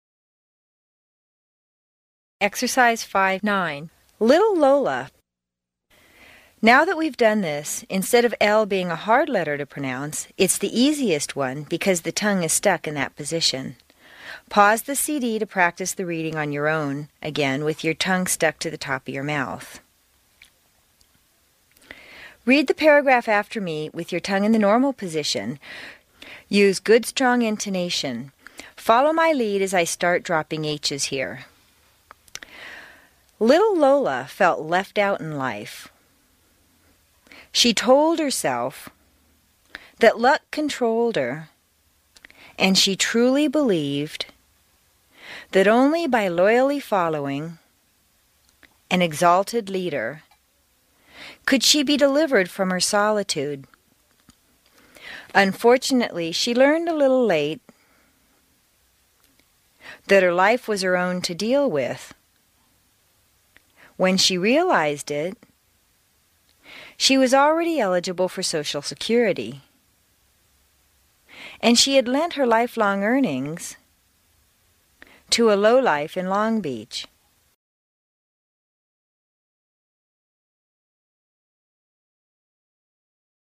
在线英语听力室美式英语正音训练第75期:练习5(9)的听力文件下载,详细解析美式语音语调，讲解美式发音的阶梯性语调训练方法，全方位了解美式发音的技巧与方法，练就一口纯正的美式发音！